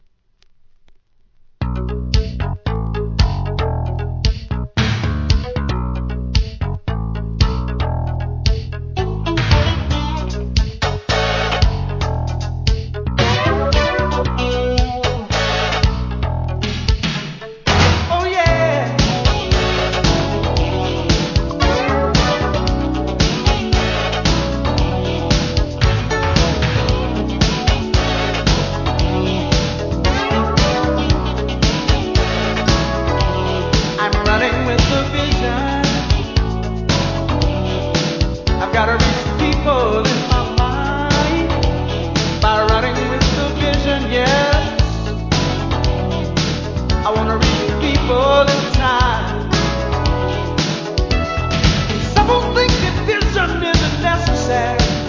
店舗 数量 カートに入れる お気に入りに追加 1987年、1st GOSPEL SOUL ALBUM!